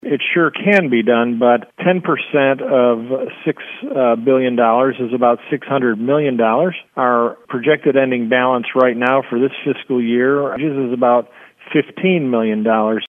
State Representative Russ Jennings explains.